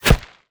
bullet_impact_dirt_06.wav